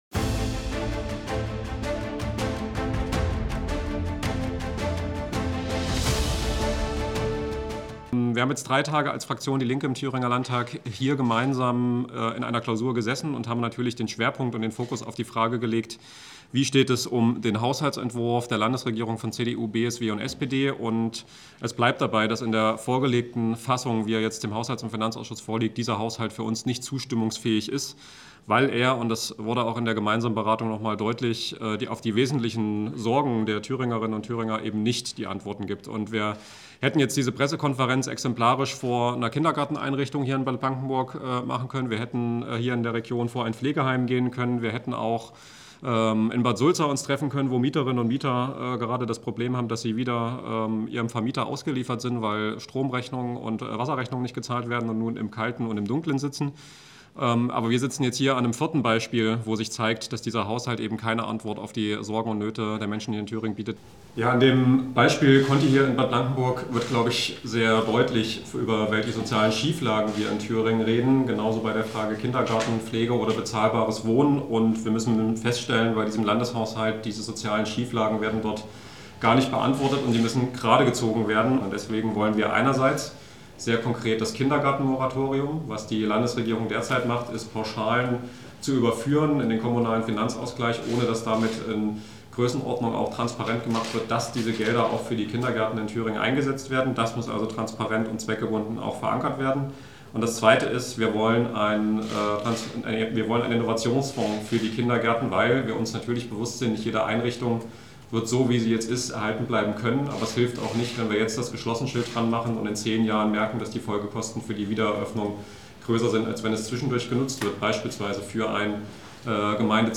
Nicht nur der Erfurter meldet sich bei diesem Thema zu Wort. Ein gemischtes Fazit.